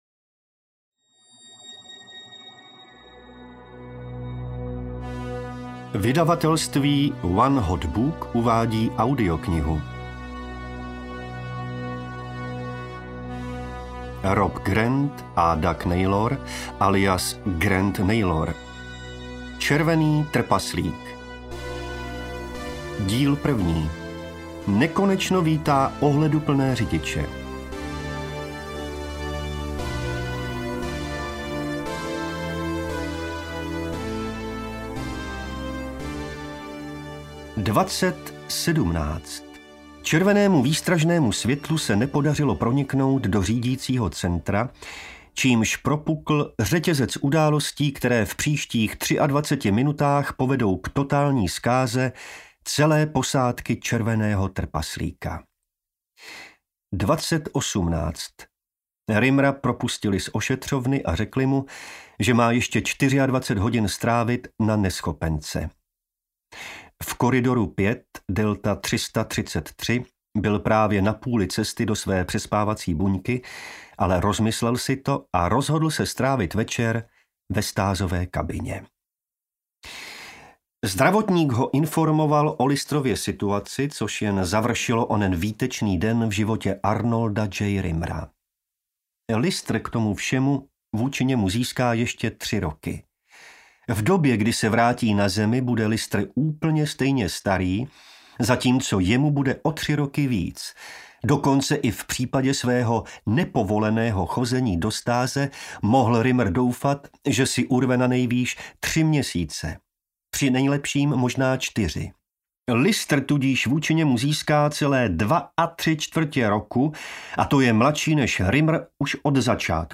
Červený trpaslík 1: Nekonečno vítá ohleduplné řidiče audiokniha
Ukázka z knihy